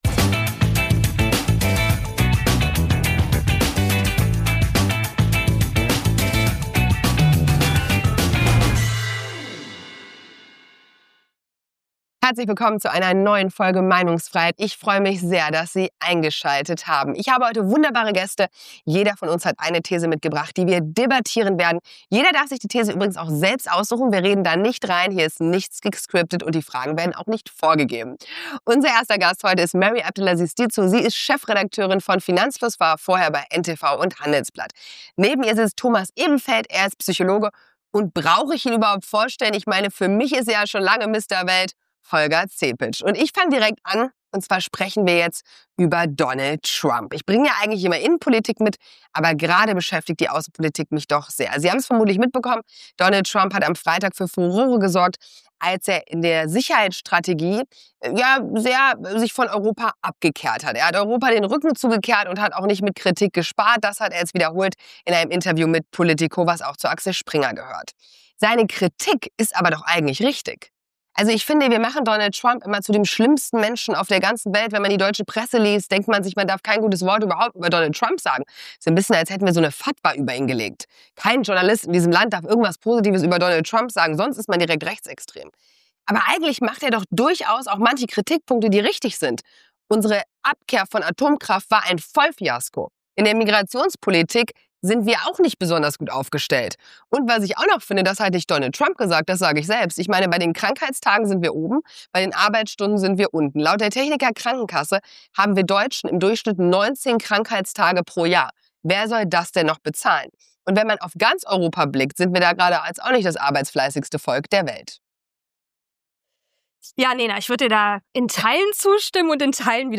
Welche Rolle spielt wirtschaftlicher Pragmatismus – und brauchen wir neue Parteien? Ein Gespräch über Führung, Verantwortung und die Frage: Ist Deutschland reformmüde oder nur schlecht regiert?
Das Konzept sieht vor, dass jeder Gast eine eigene These mit in die Sendung bringt und diese mit den anderen Gästen und der Moderation diskutiert.